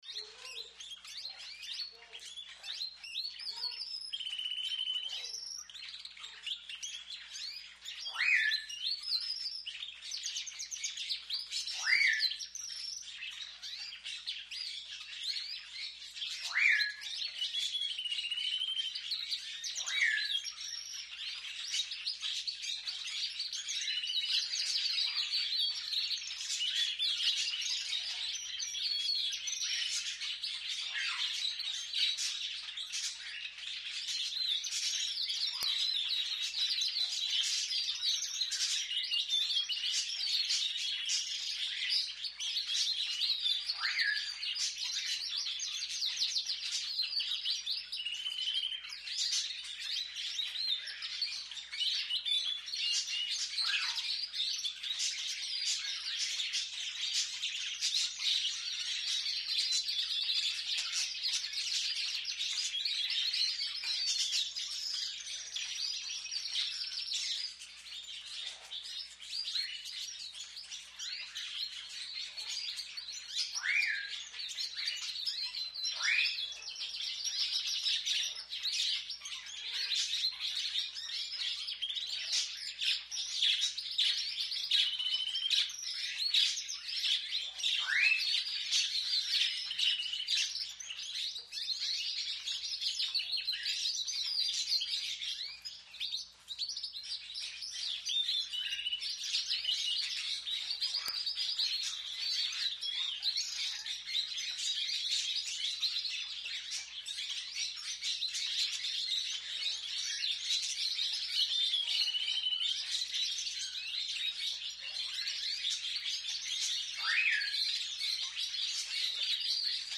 Tropical birds, Latin America, birdhouse recording